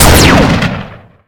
gun1.ogg